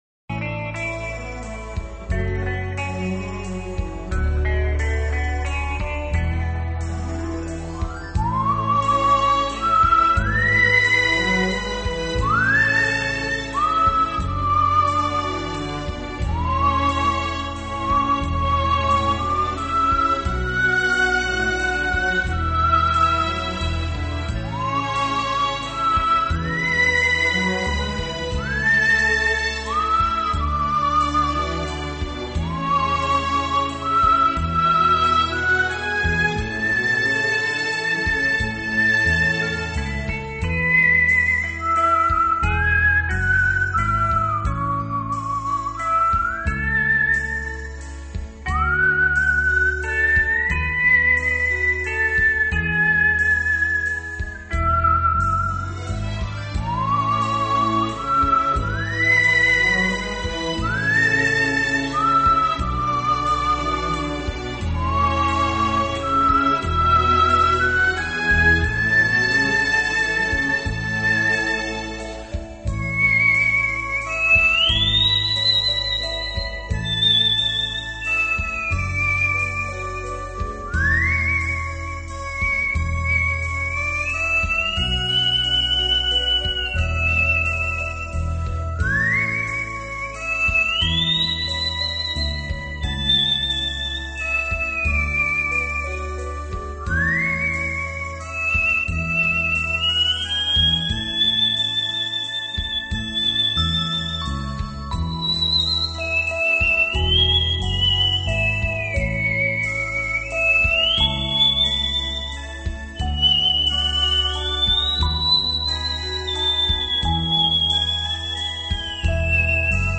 最动听的口哨音乐
最朴素的声音带来了一股清凉的风
透明、神秘的口哨声充满了朦胧的氛围
为人们带来内心的平静